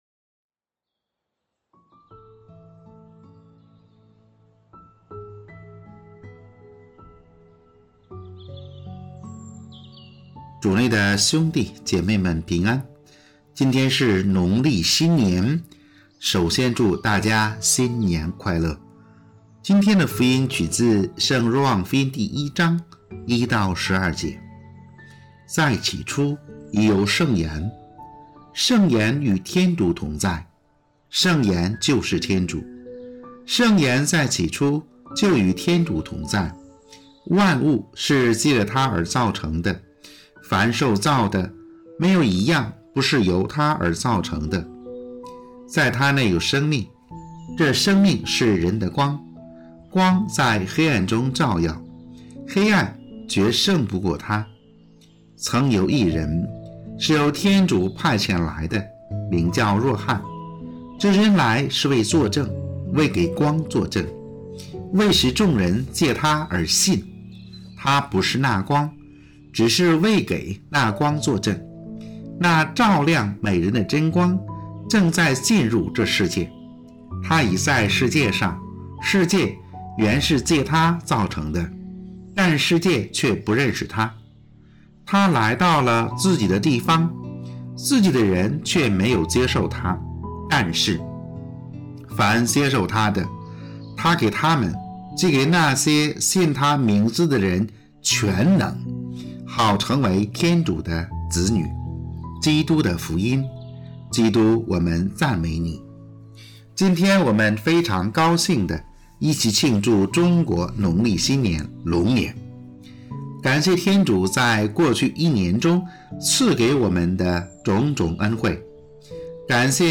【主日证道】| 惜福，享福，传福（农历新年）
农历新年